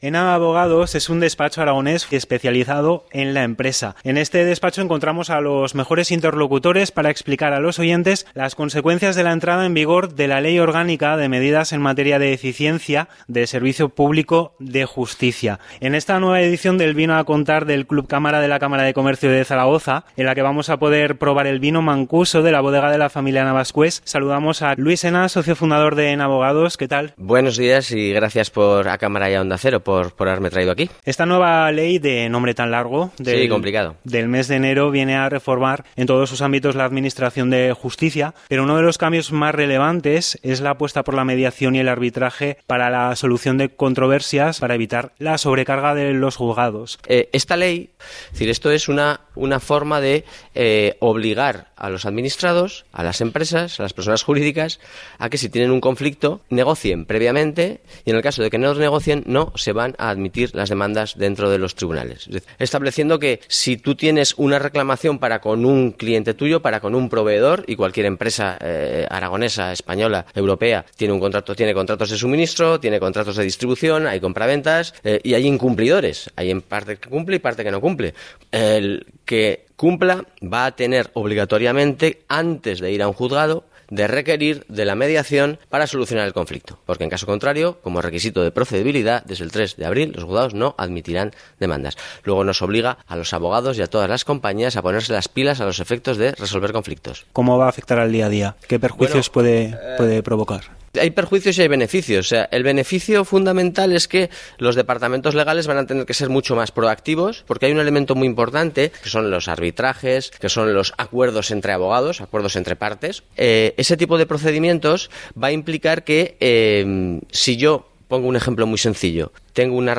A lo largo de la semana la radio emitirá la entrevista en diferentes programas de Onda Cero, «Más de Uno Zaragoza» y «La Brújula».
Aquí os dejamos el audio de la entrevista